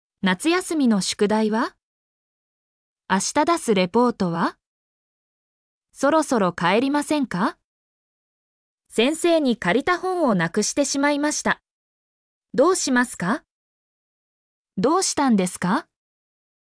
mp3-output-ttsfreedotcom-26_EdXpAHAI.mp3